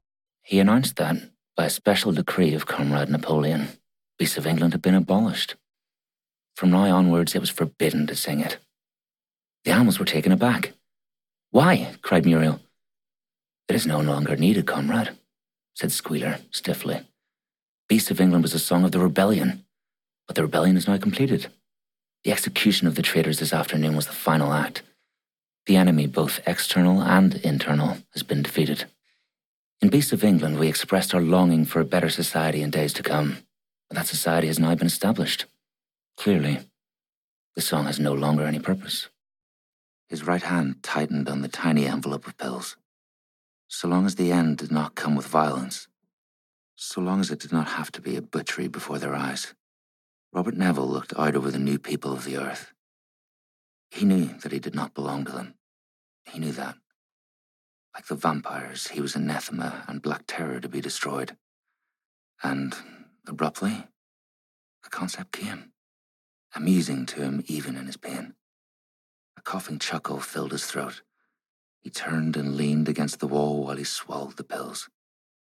20s-40s. Male. Northern Irish.
Audiobook